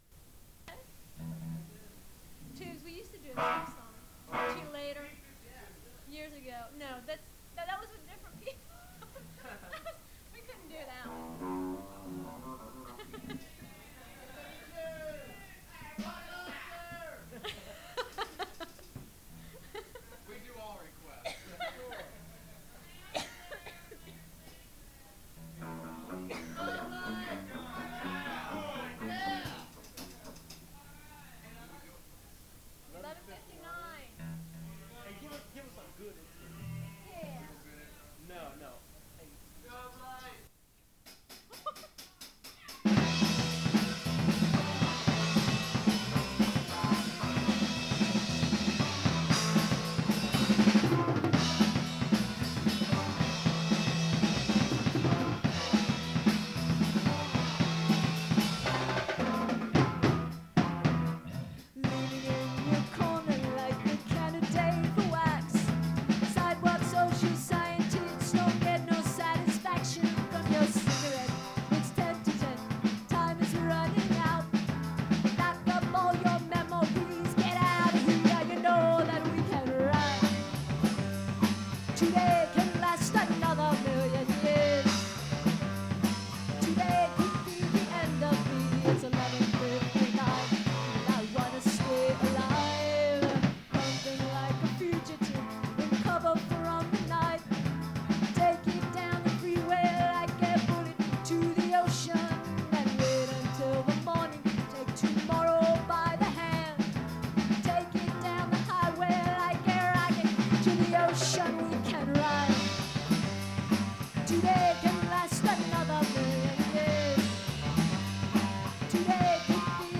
This is the third set from the night.
rocker